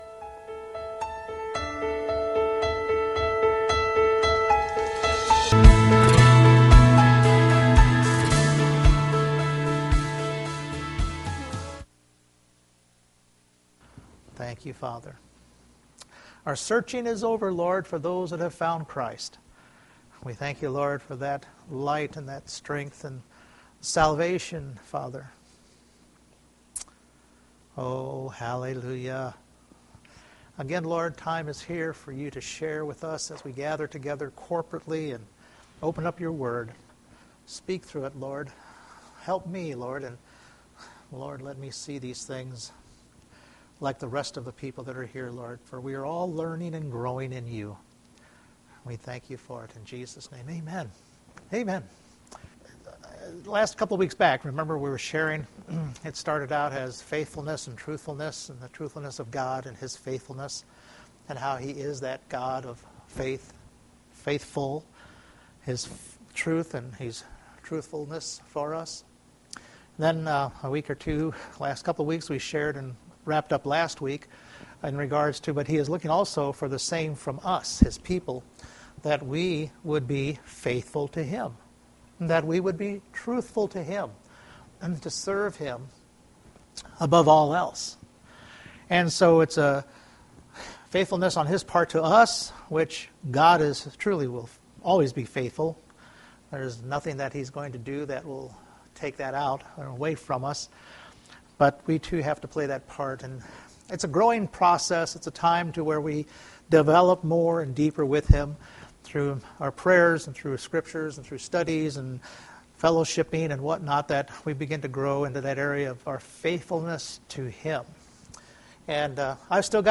Mark 14:3-9 Service Type: Sunday Morning We too are to be broken and spilled out in our serving God in our lives.